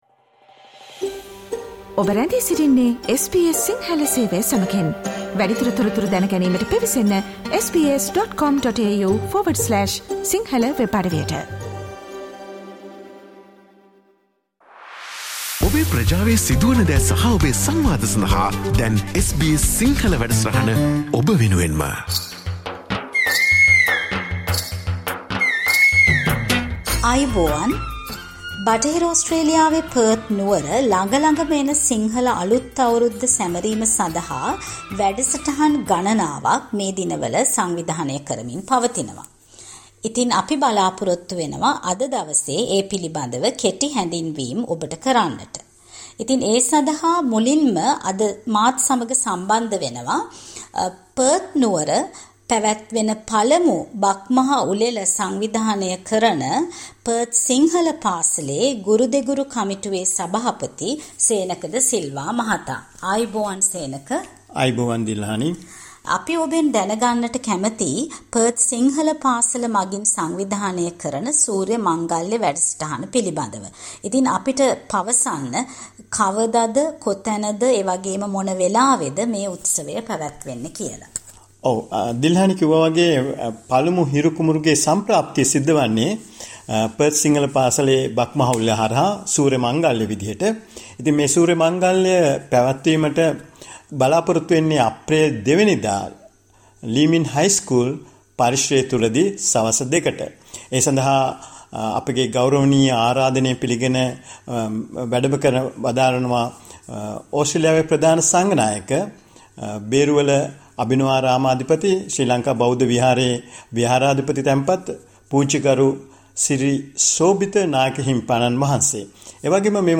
Listen to SBS Sinhala Radio's discussion on preparations for this year's Sinhala New Year in Western Australia.